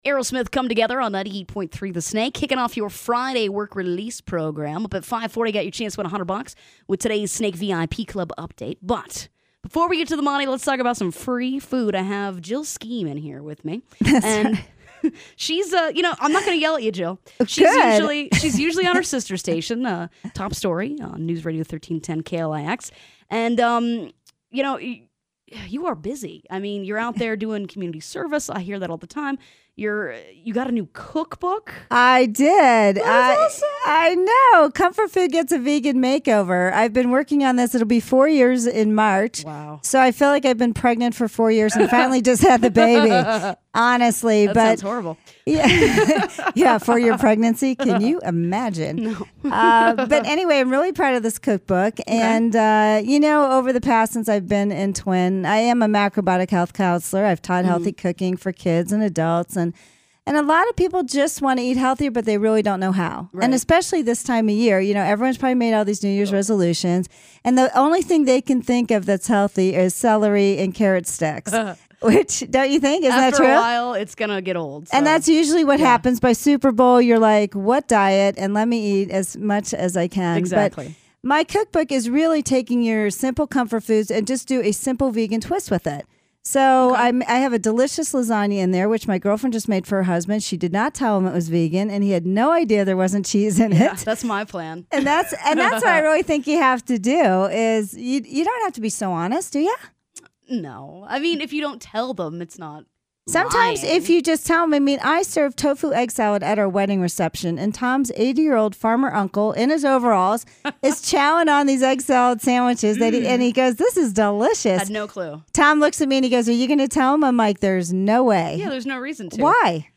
Here's my entire interview